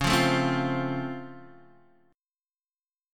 C#+M7 chord